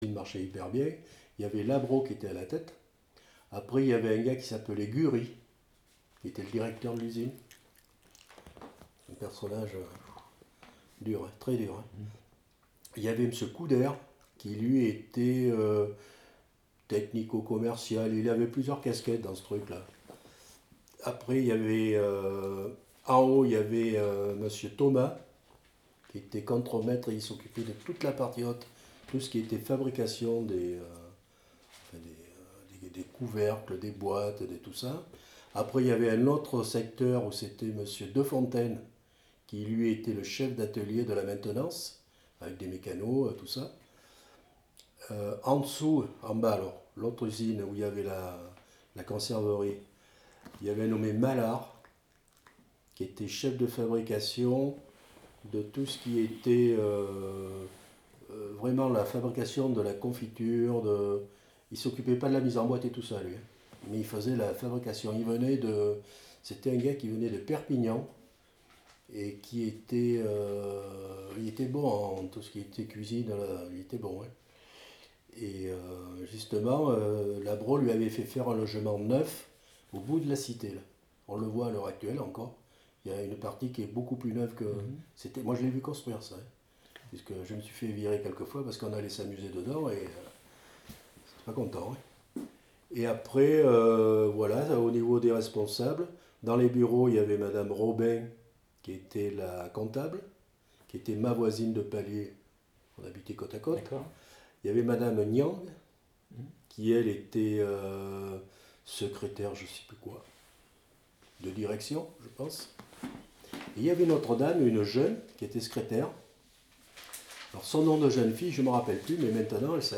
Mémoire de l'usine Labro, interview